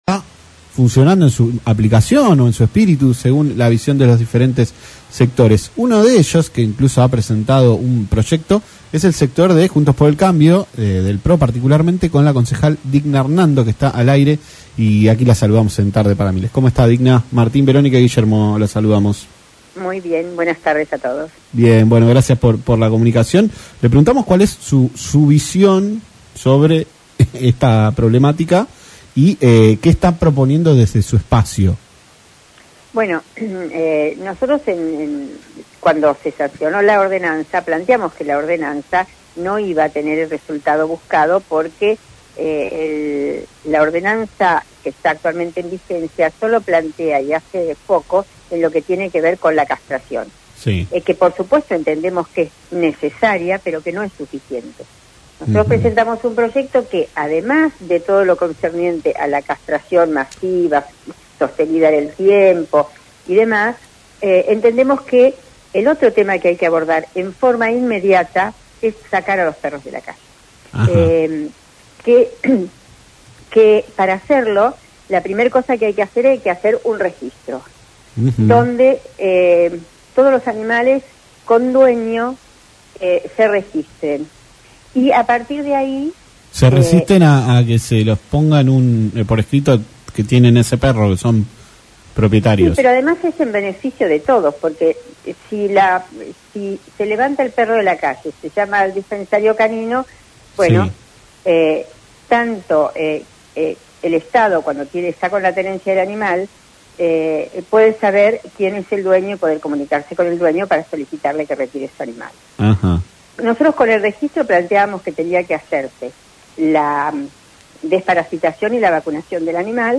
Digna Hernando, Concejal de Comodoro Rivadavia por Juntos por el Cambio, habló en Tarde Para Miles por LaCienPuntoUno sobre la superpoblación canina en la localidad y la propuesta para modificar la ordenanza actual.